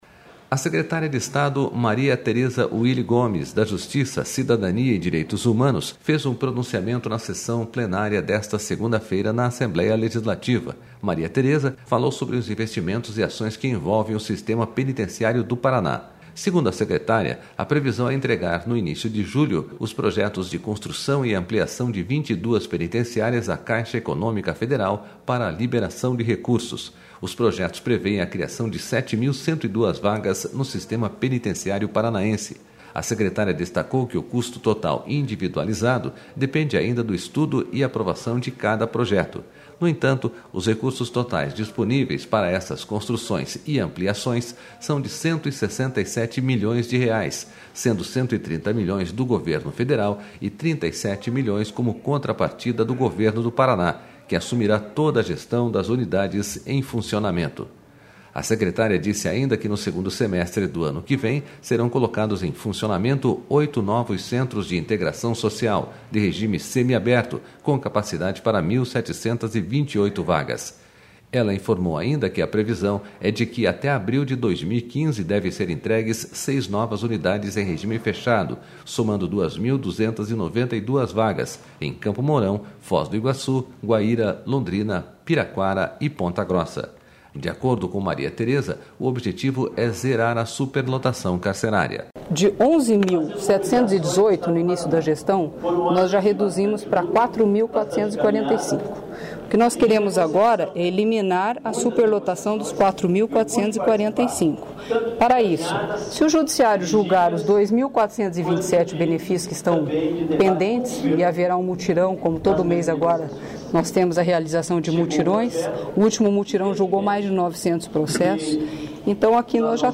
A secretária de Estado Maria Teresa Uille Gomes, da Justiça, Cidadania e Direitos Humanos, fez um pronunciamento na sessão plenária desta segunda-feira, na Assembleia Legislativa.//
O pronunciamento aconteceu no horário do Grande Expediente da sessão, conforme proposição do deputado Ademar Traiano, PSDB e líder do Governo no Legislativo.//